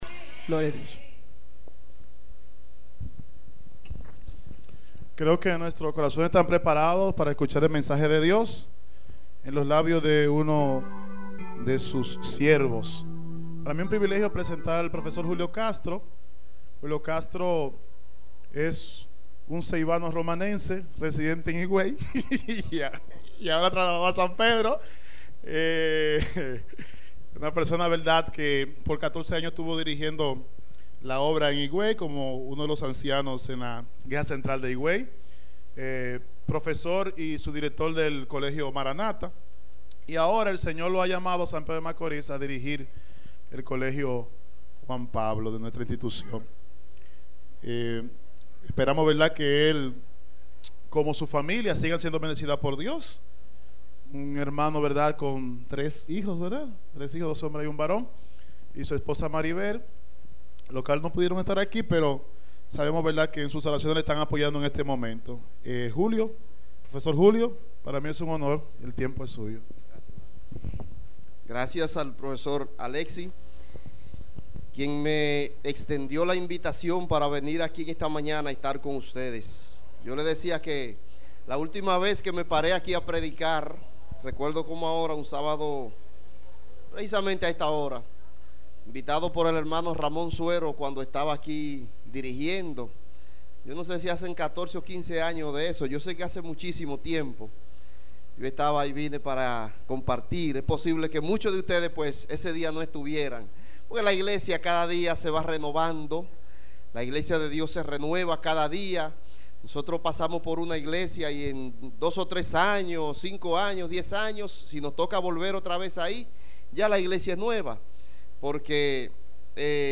Sermón basado en la visita de Jesús l templo a los 12 años.